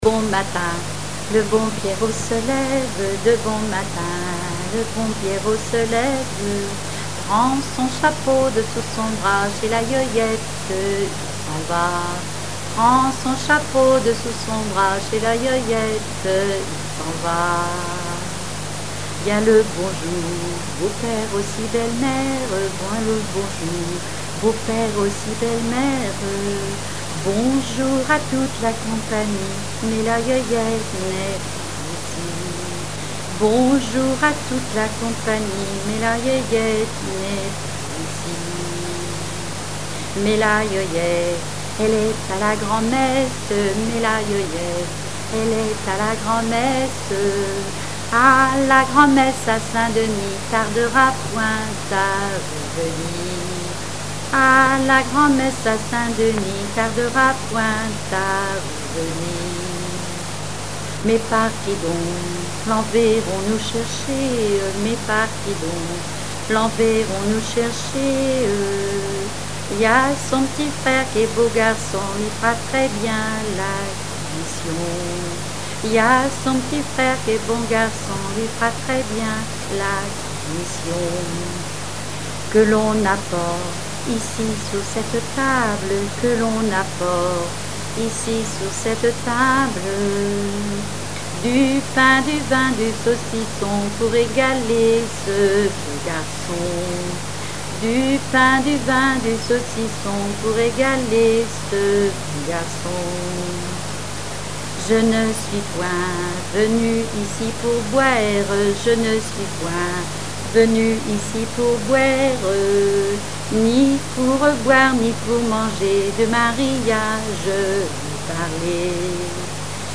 Genre strophique
répertoire de chansons populaire et traditionnelles